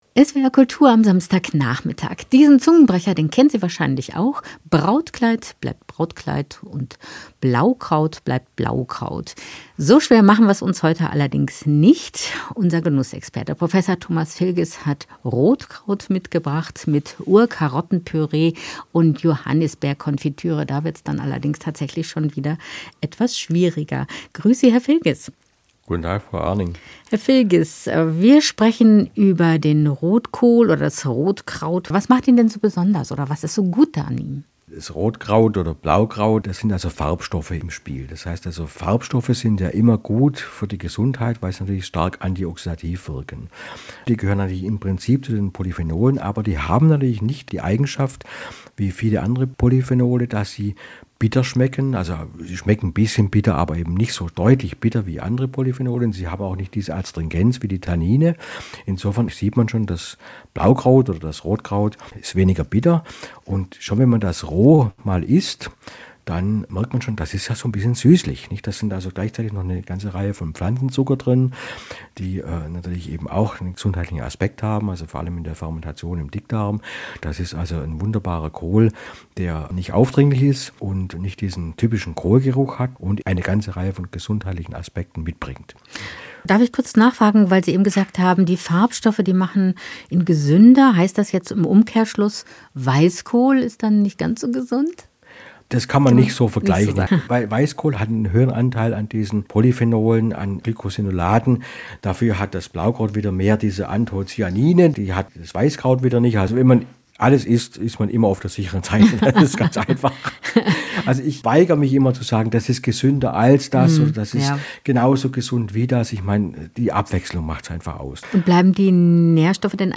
Das Gespräch führte
Gespräch mit